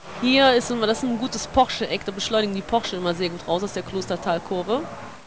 Sabine comments on a lap of the nr.44 Nissan Skyline